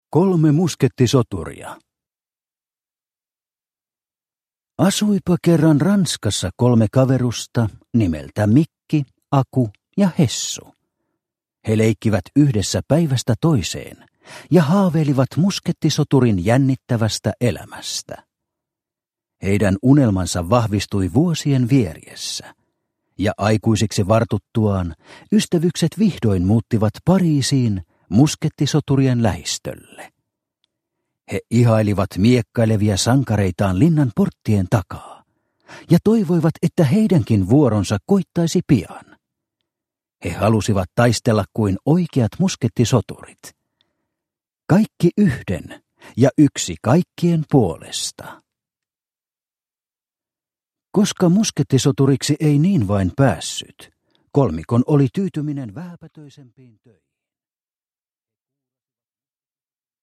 Kolme muskettisoturia – Ljudbok – Laddas ner